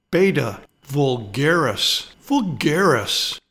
Pronounciation:
BAY-ta vul-GAR-is vul-GAR-is